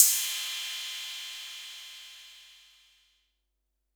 Tr8 Cymbal 03.wav